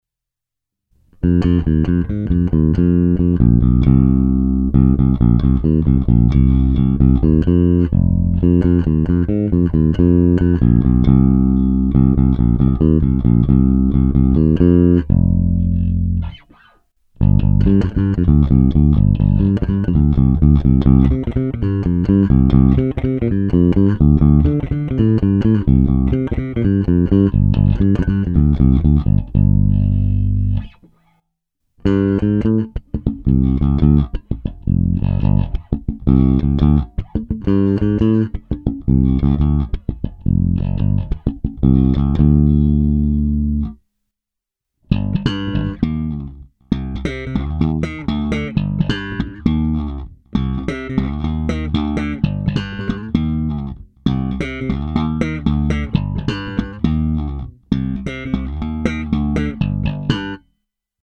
Kobylkový snímač